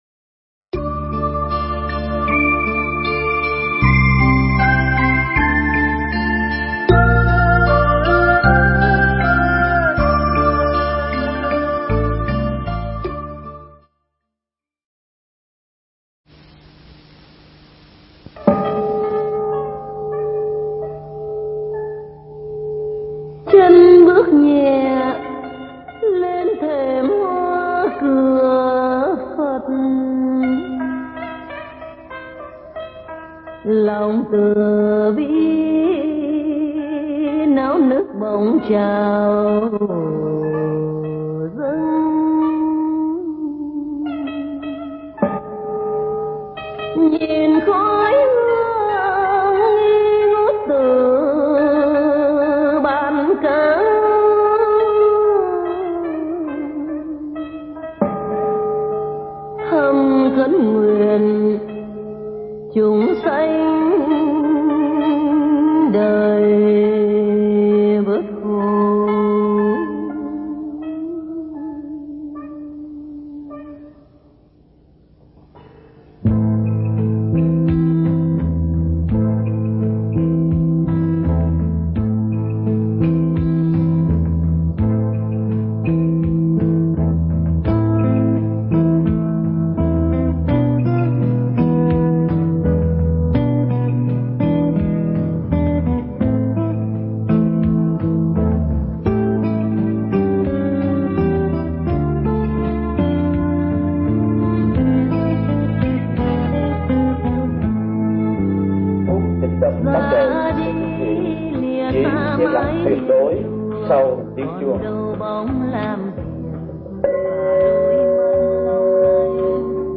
Mp3 Pháp Thoại Cửa Thiền – Hòa Thượng Thích Thanh Từ giảng tại Bankstown, Australia, ngày 24 tháng 11 năm 1996